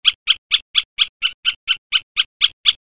Hackspett med fågelläte
Fågelns autentiska fågelläte är inspelat och kommer som vacker sång när du trycker den på magen.
Så här låter hackspetten:
Great-Spotted-Woodpecker.mp3